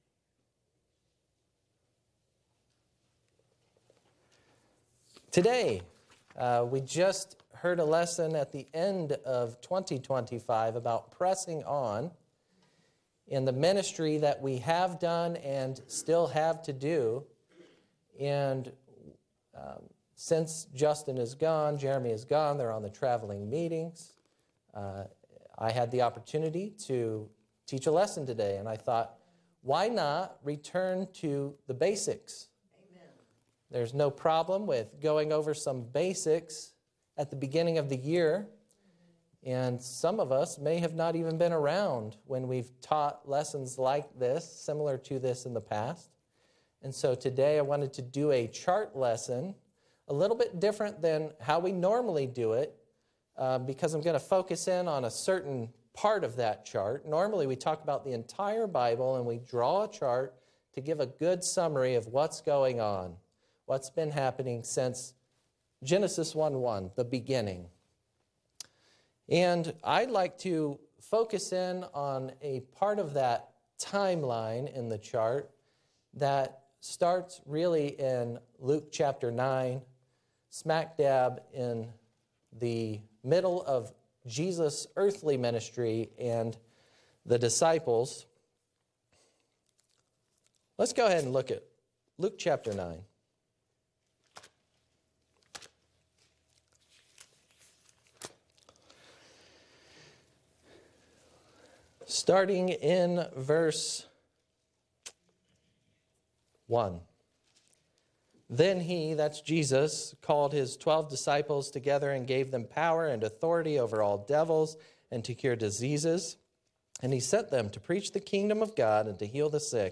Chart Lesson: Jesus’ Secret Gospel for Paul